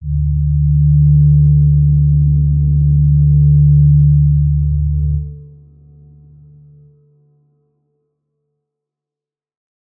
G_Crystal-D3-mf.wav